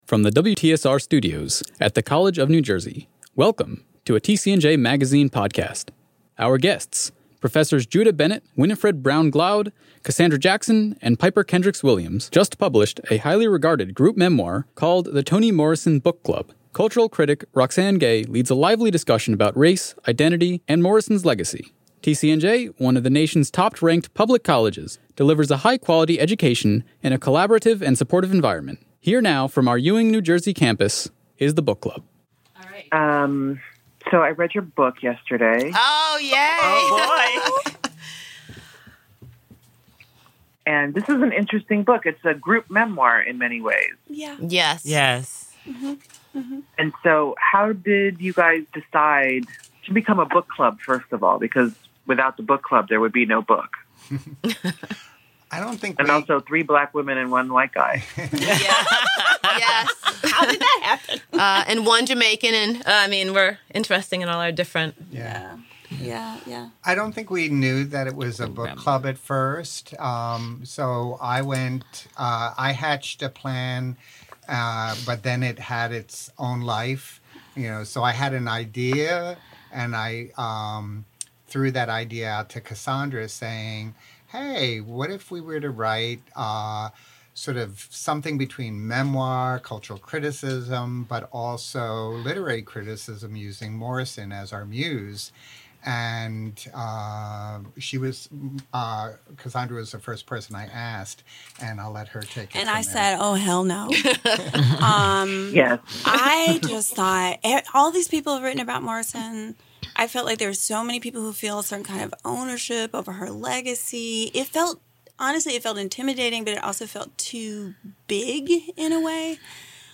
via Skype